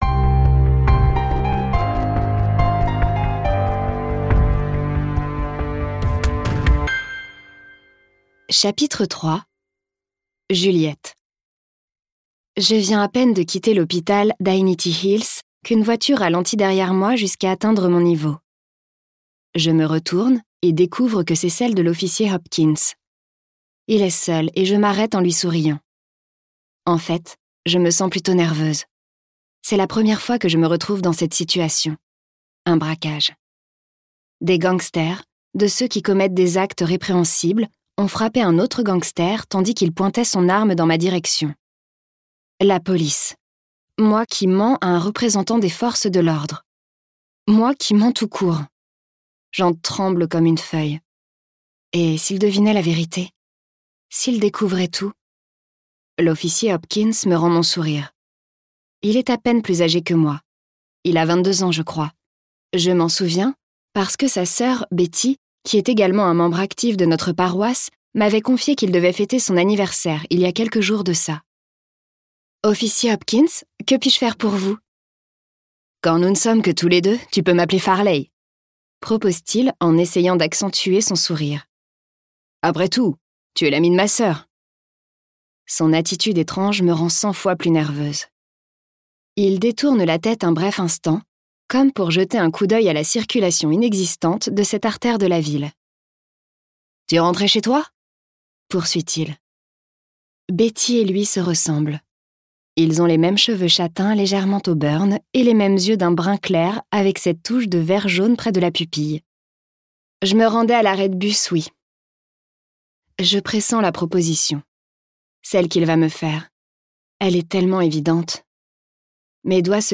En a-t-elle seulement envie ?Ce livre audio est interprété par une voix humaine, dans le respect des engagements d'Hardigan.